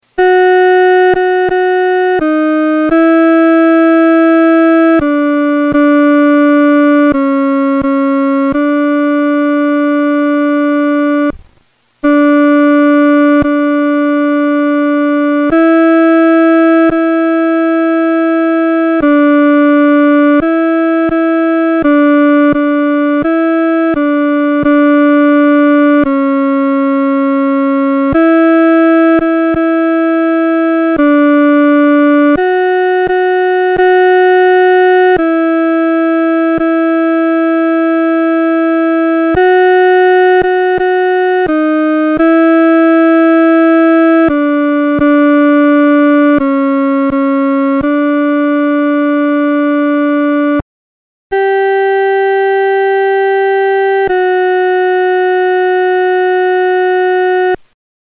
伴奏
女低